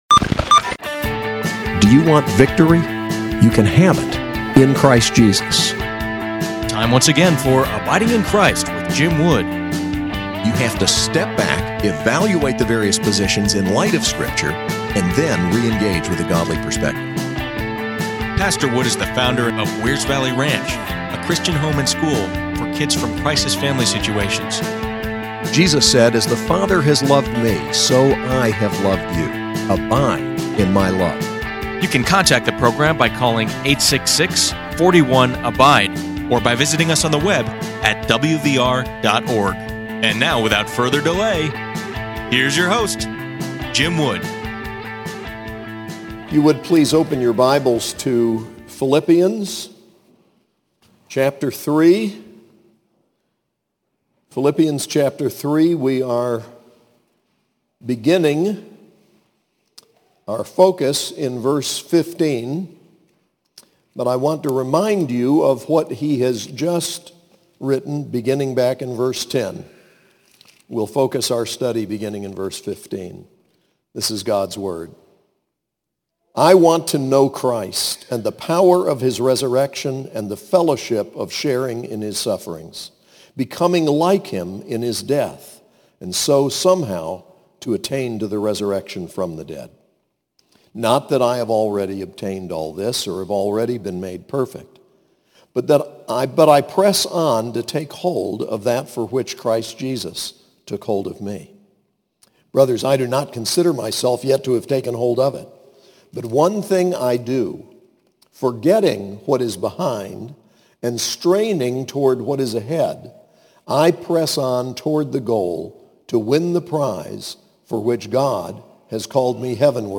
SAS Chapel: Philippians 3:10-4:1